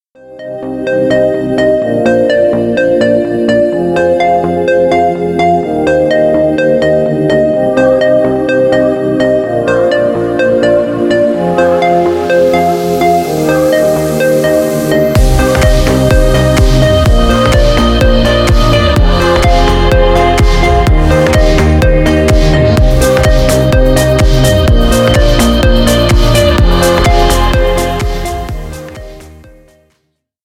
Поп Музыка
тихие # без слов